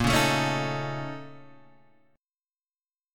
A#mM7bb5 chord {6 4 7 6 4 5} chord